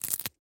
Звуки наклеек
Звук отрыва ценника с товара